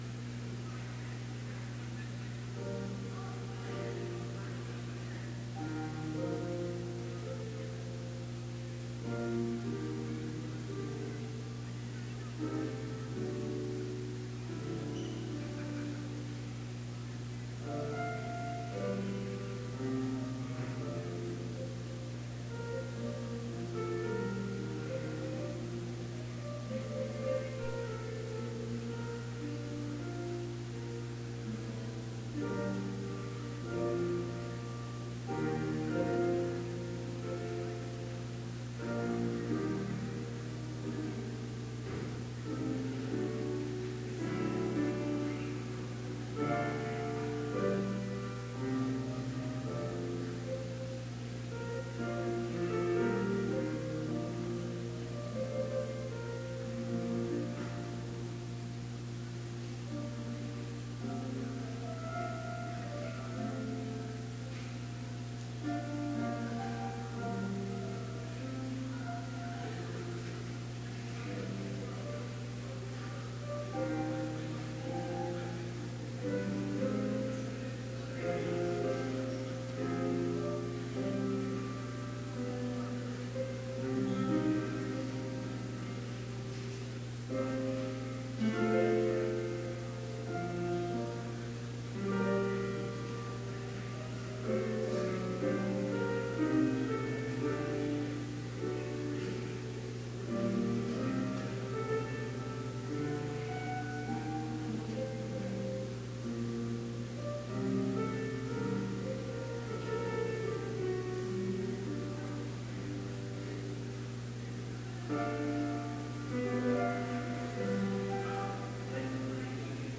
Complete service audio for Chapel - February 9, 2018